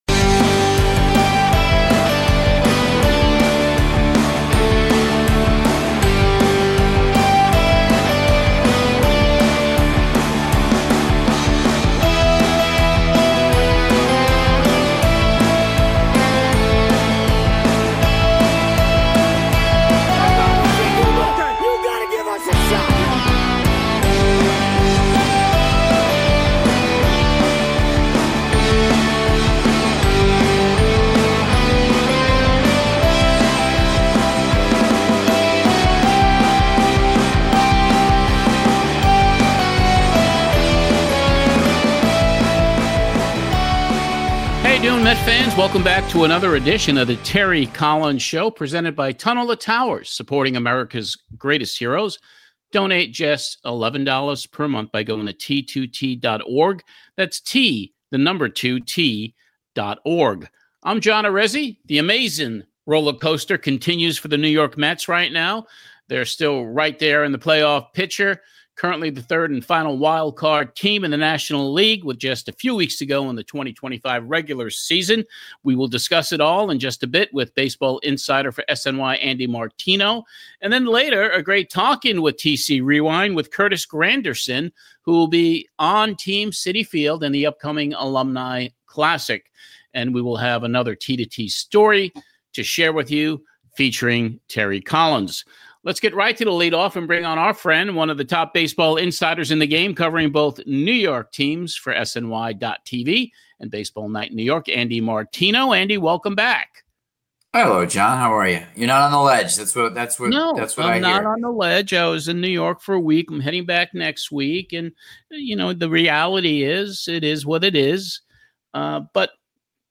We feature two special Tunnel to Towers stories, narrated by Terry Collins.